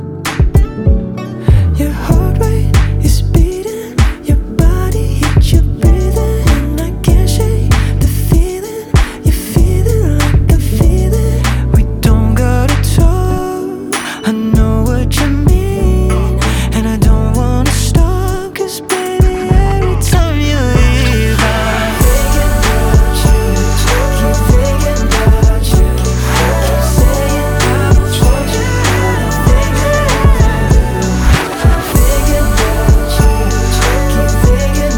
2025-08-08 Жанр: Поп музыка Длительность